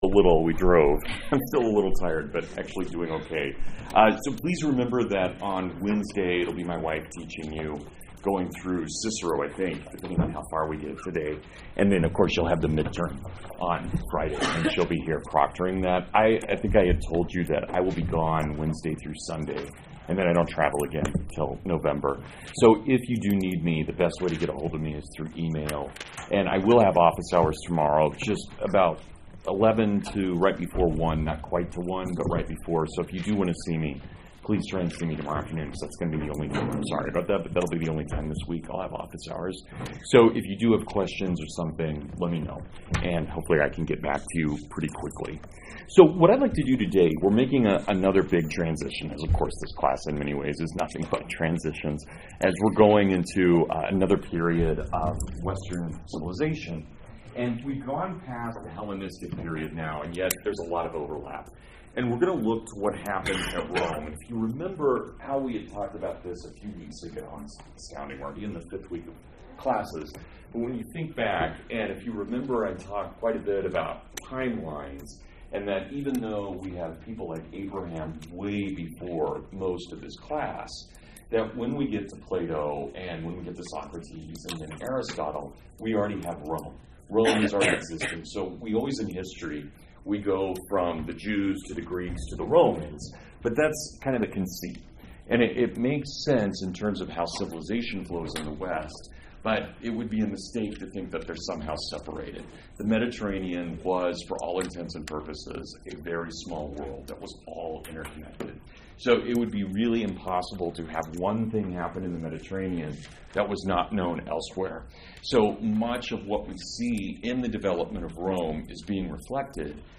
Western Heritage Lecture 15: The Roman Republic